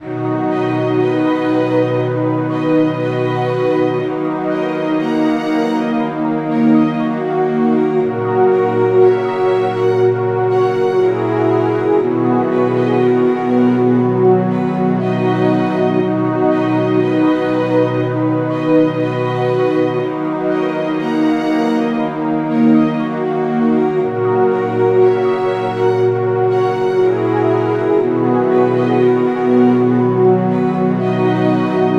Ich habe 2 Presets genommen und diese jeweils mit verschiedenen Instrumenten ausgestattet. Außerdem habe ich das Tempo verändert.